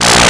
Hum11.wav